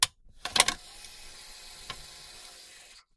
Звуки сейфа
Звук открывающейся двери электронного сейфа